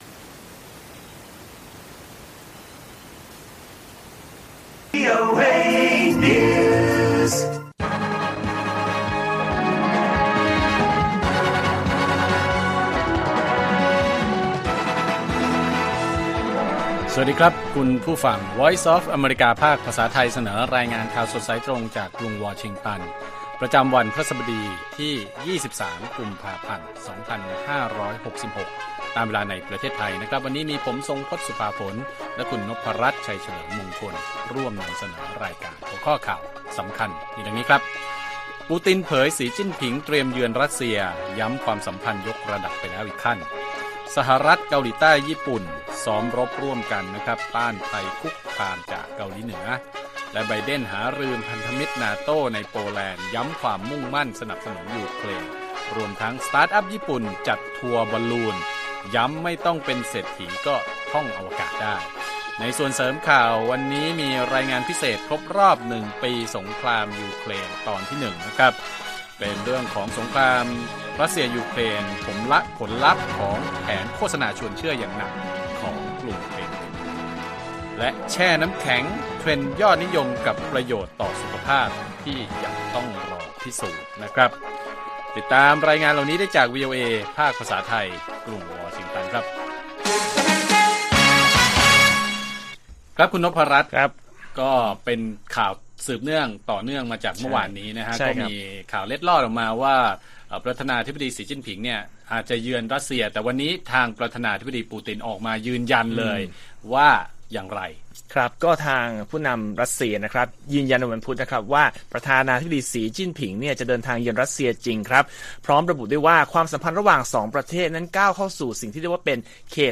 ข่าวสดสายตรงจากวีโอเอ ไทย พฤหัสบดี 23 ก.พ. 2566